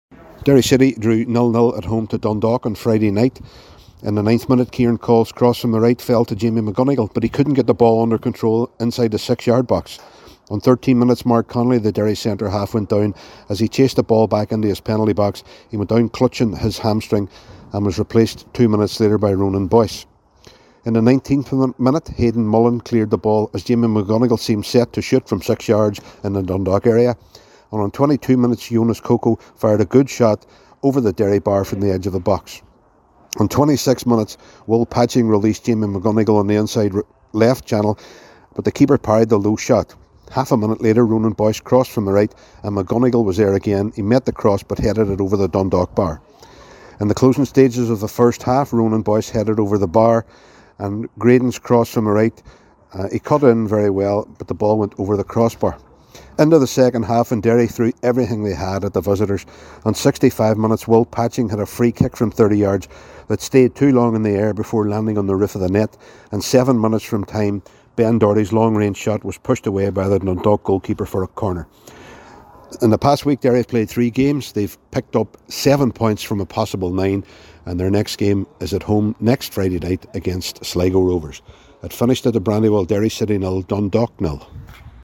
reports for Highland Radio Sport: